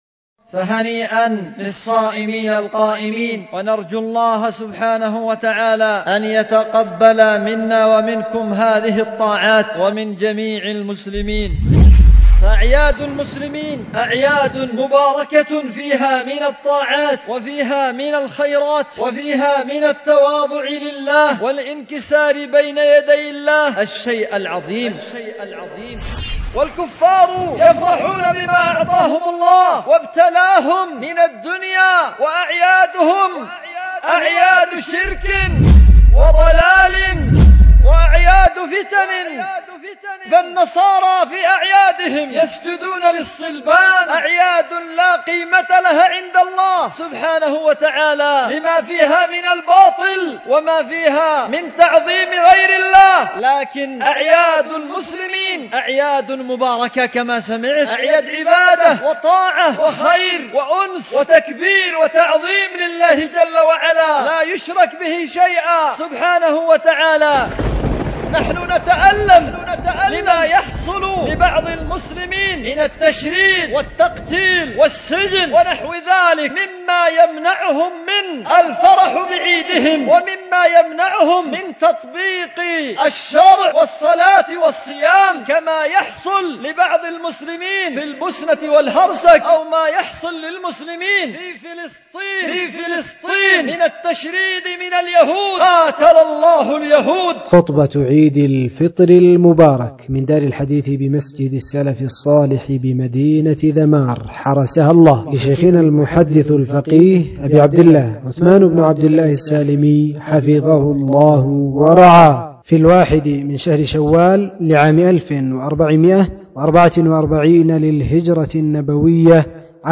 خطبة عيد الفطر المبارك 1444